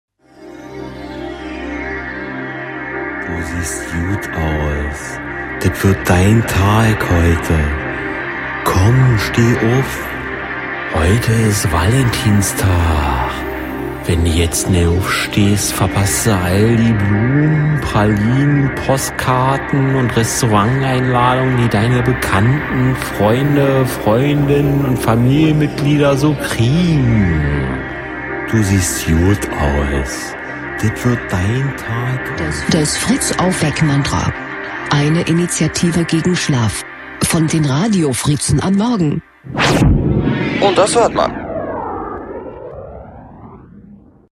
Fritz Aufweck-Mantra 14.02.17 (Valentinstag) | Fritz Sound Meme Jingle